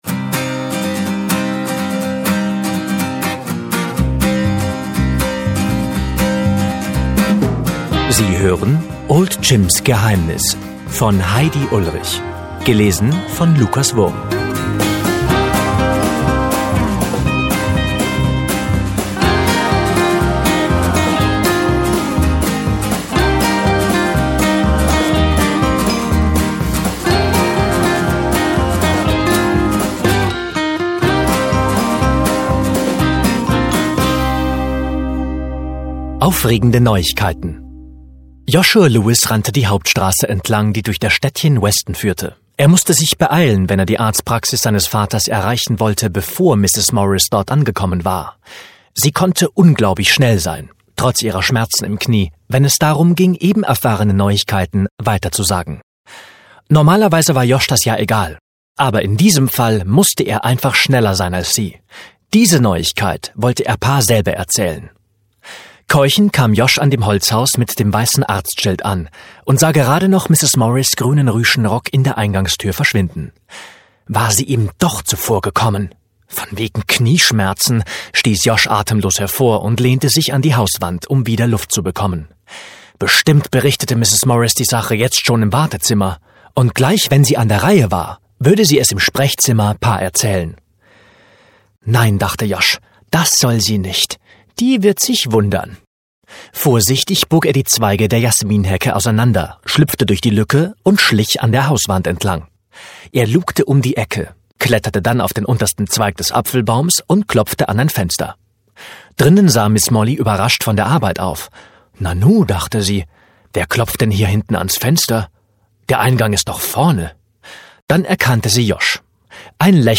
Old Jims Geheimnis (MP3-Hörbuch)